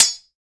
knife_hitwall2.wav